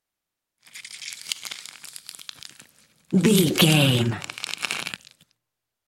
Creature eating flesh peel
Sound Effects
Atonal
ominous
disturbing
eerie